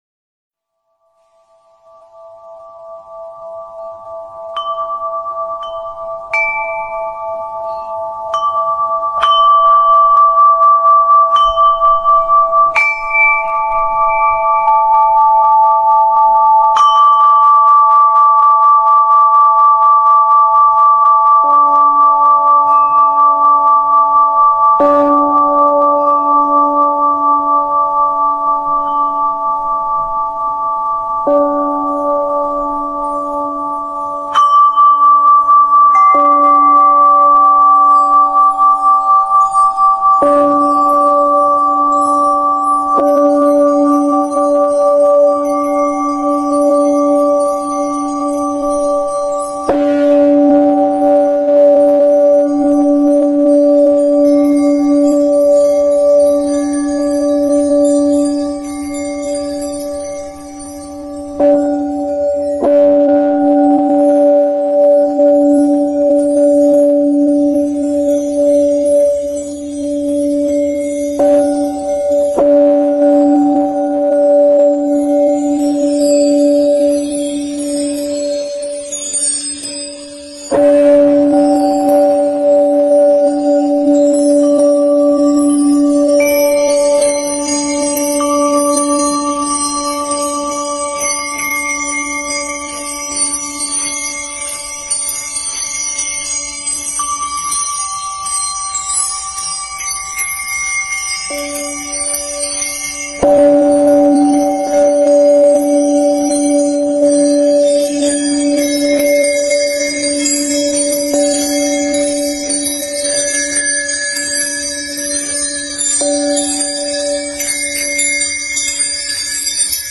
Each singing bowl possesses a unique texture and sound frequency.
The deep, resonant sounds of the bowls seem to instantly bring individuals into a state of physical and mental relaxation when struck by the holder.
singinging-bowl.mp3